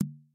box_select_boom.mp3